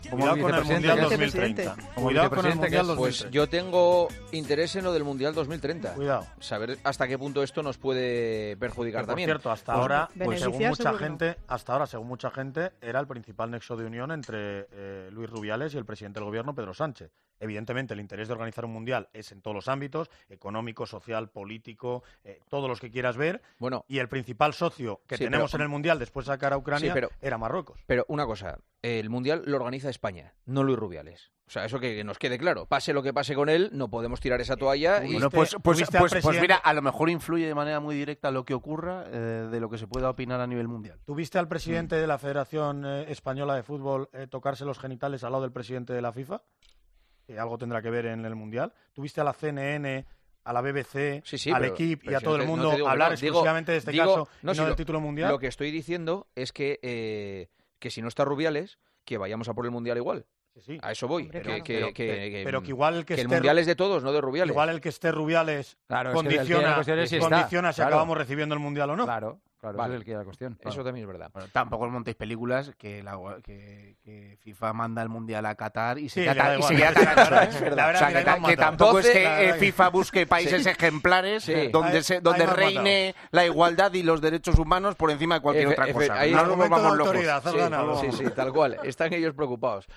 La preocupación sobre el Mundial de los tertulianos de El Partidazo de COPE